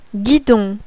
GUIDON.wav